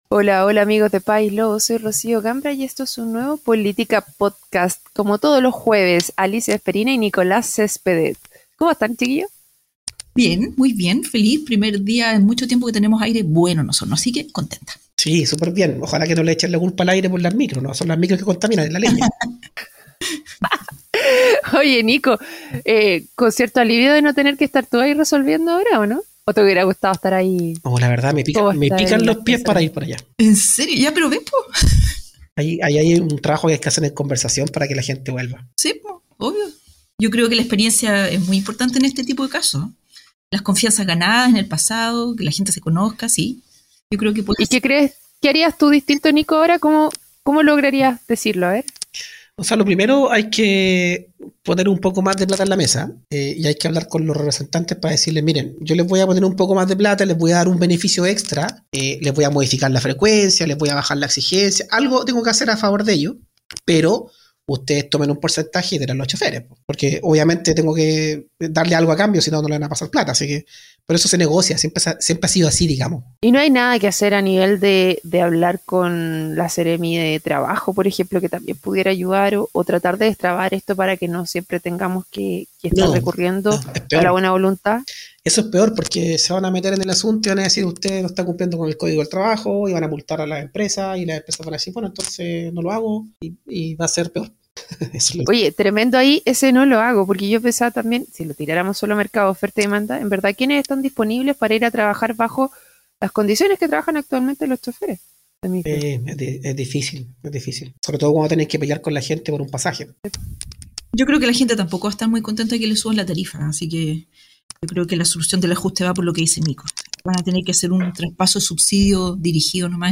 En este Política Podcast tres ingenieros